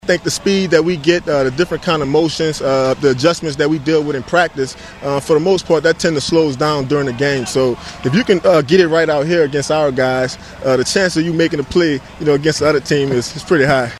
Safety Tyrann Mathieu says going up against the Chiefs offense is a benefit.